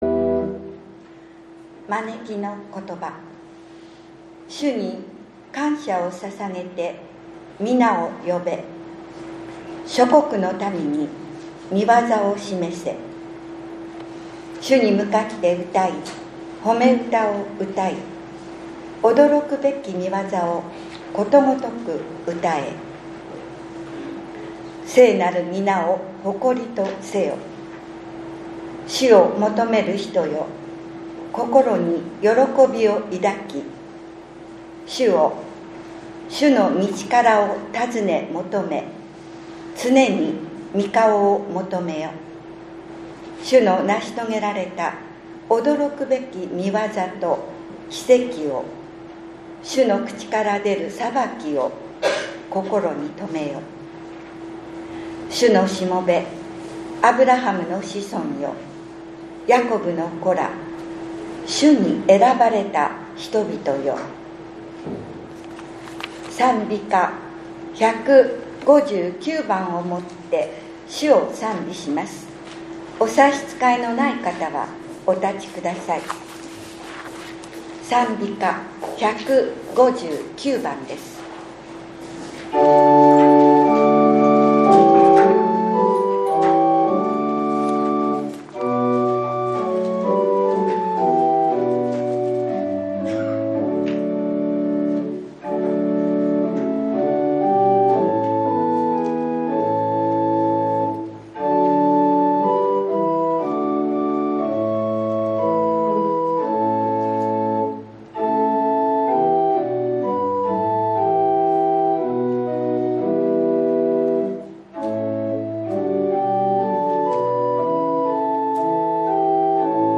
１月１８日（日）主日礼拝